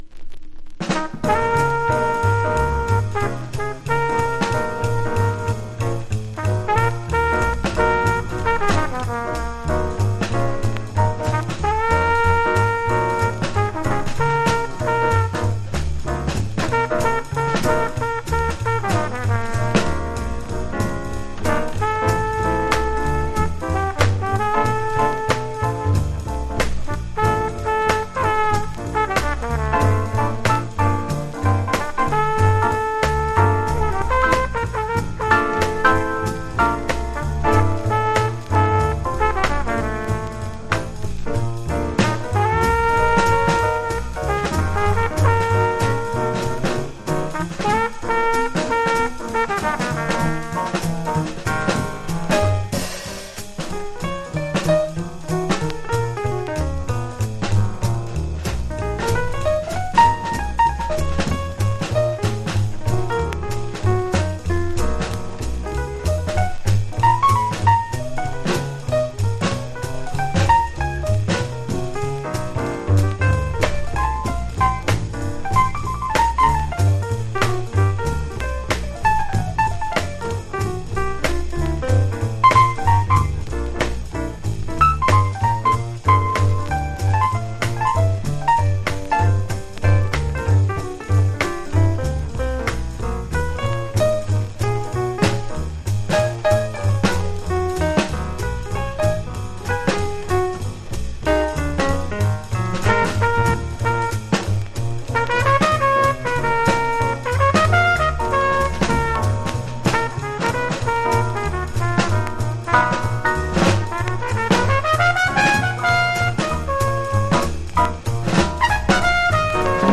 （小傷によりチリ、プチ音ある曲あり）
MONO
Genre US JAZZ